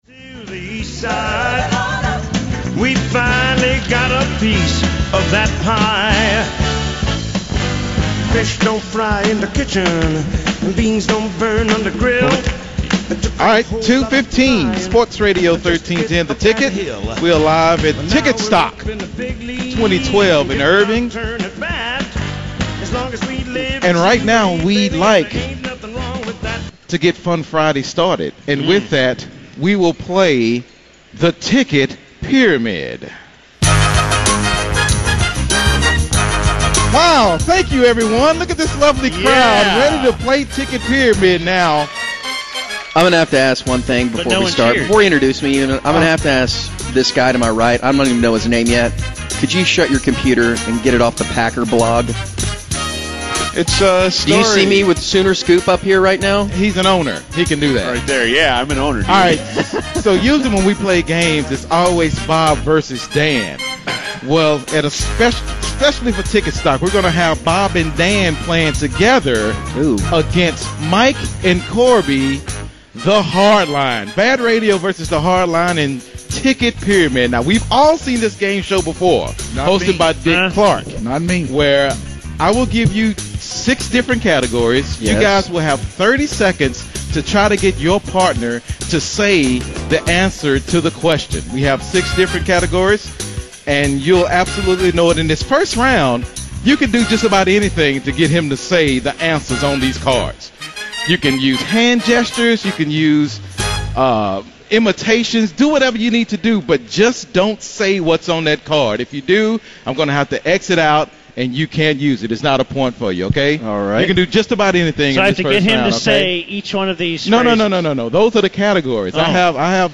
BaD Radio kicked off TicketStock on Friday with the TicketStock Pyramid game show.